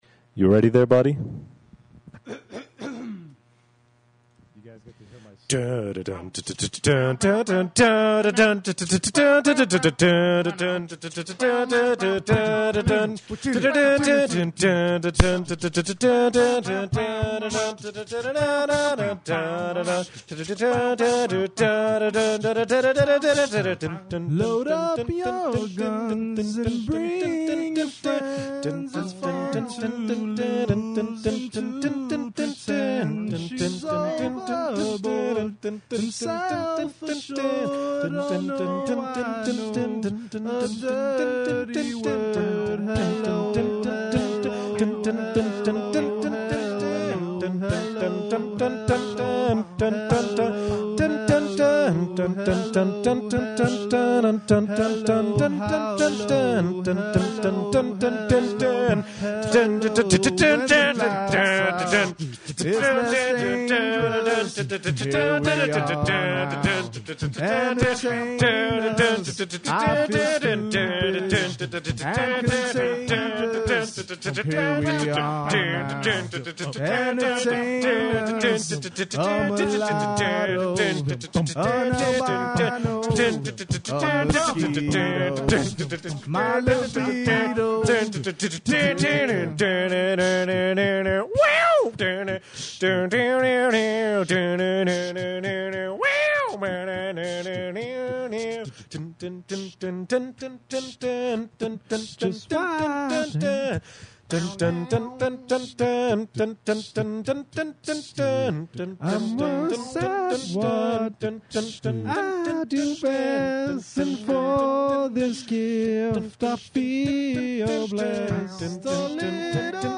all singing version